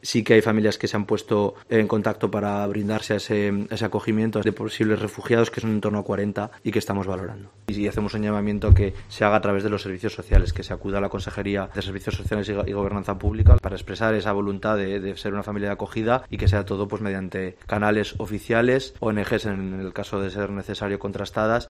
Álex Dorado, portavoz del Gobierno de La Rioja